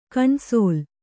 ♪ kaṇsōl